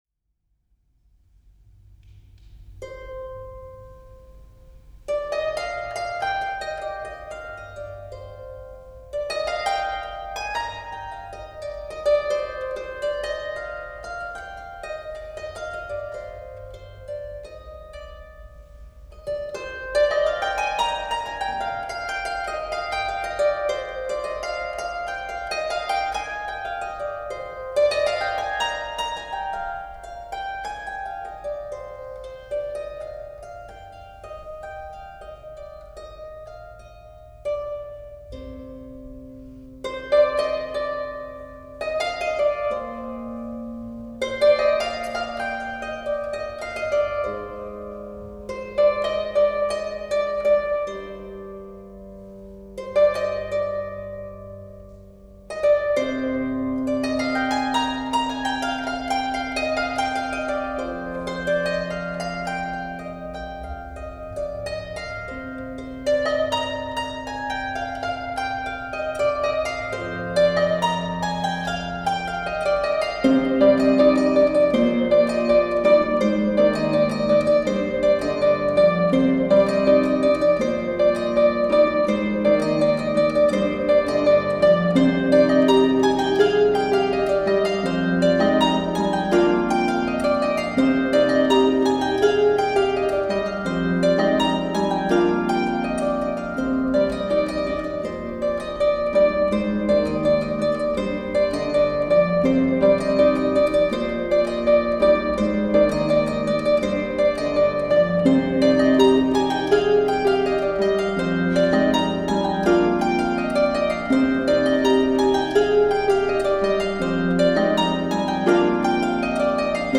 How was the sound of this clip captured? Live recording at HelgaTrefaldighets Church, Uppsala 25 April 2014.